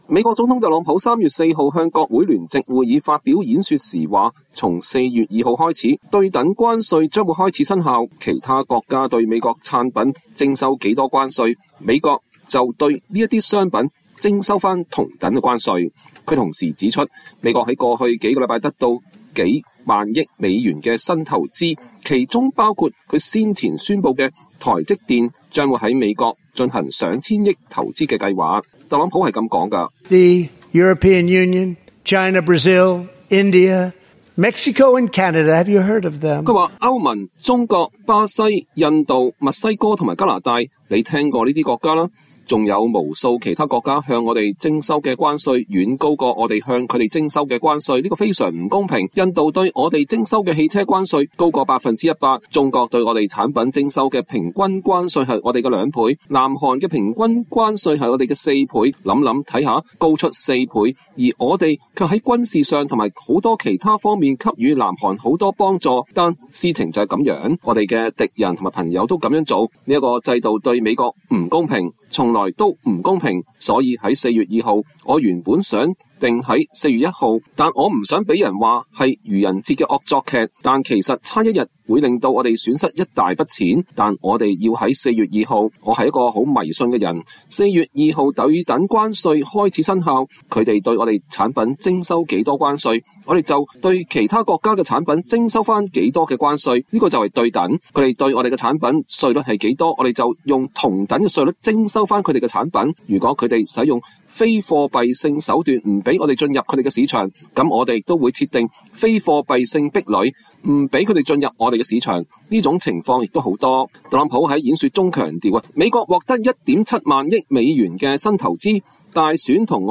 美國總統特朗普3月4日向國會聯席會議發表演說。